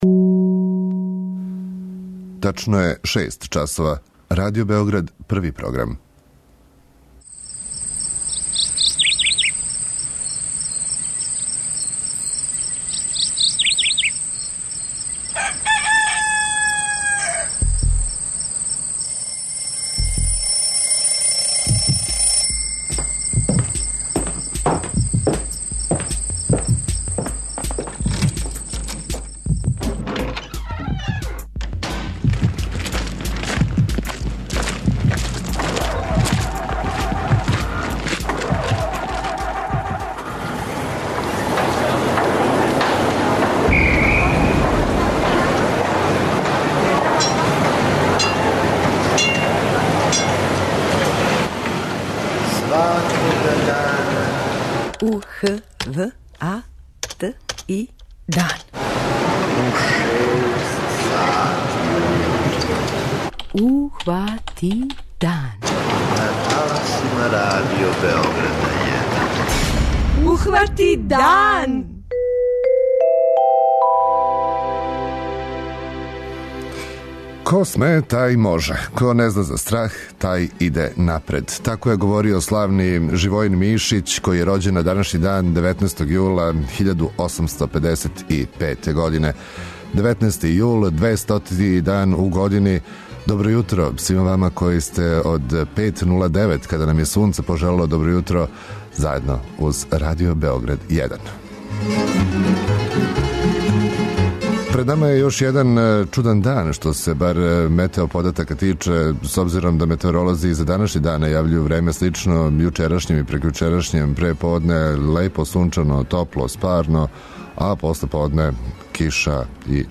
преузми : 57.32 MB Ухвати дан Autor: Група аутора Јутарњи програм Радио Београда 1!